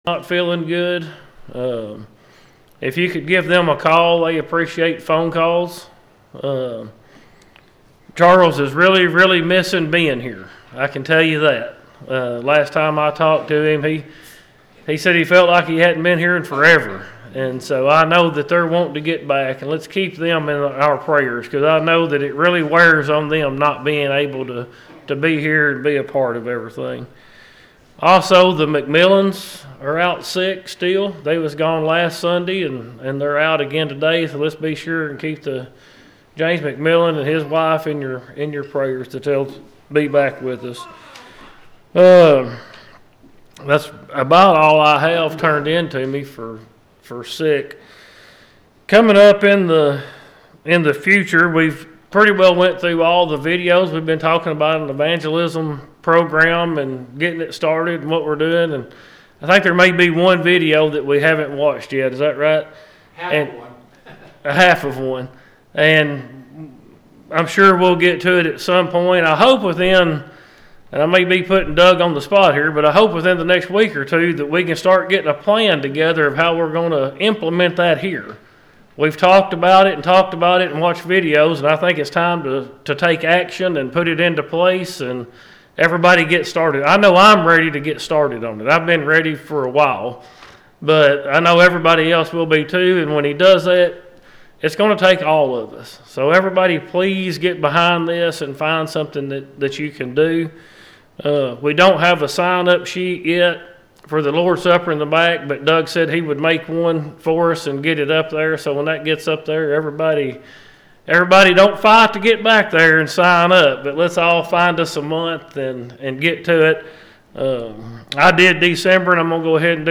The sermon is from our live stream on 1/4/2026